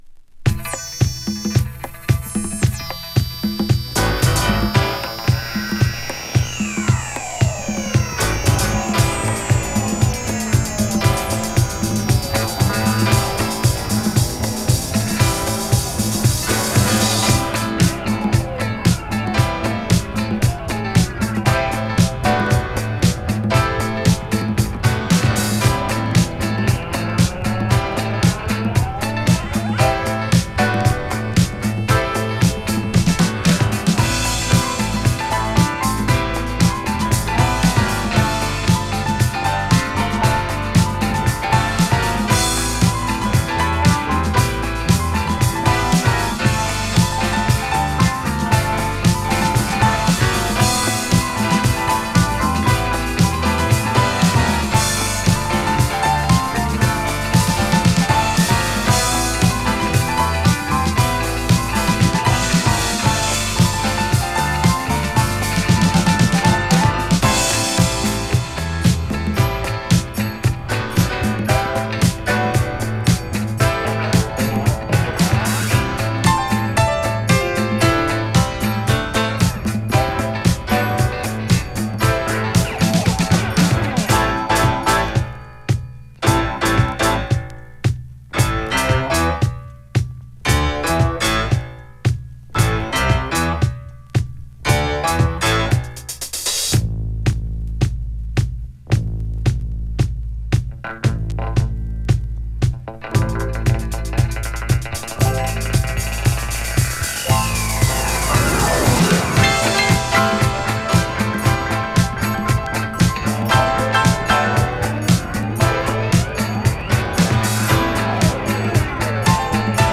魅惑のストリングスワークや電化したグルーヴで、現行のディスコシーンでも人気の高い。